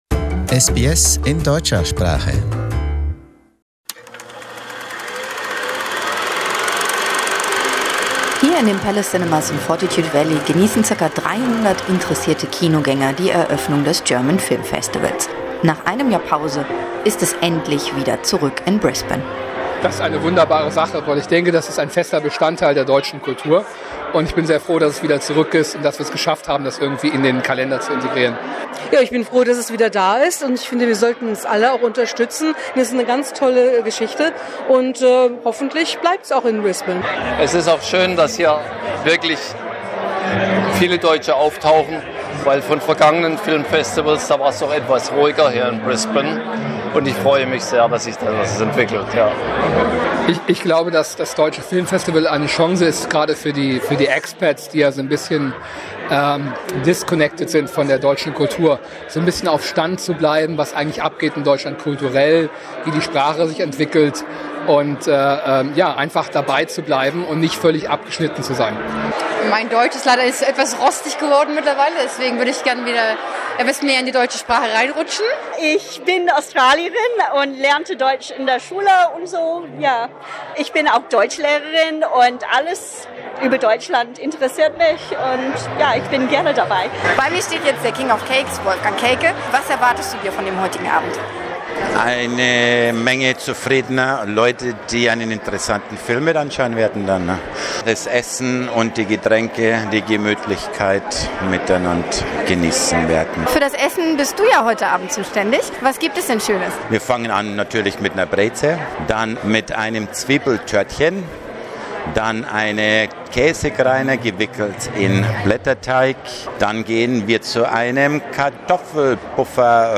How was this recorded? reports from the opening night and has mixed with the - predominantly German speaking - cinema audience.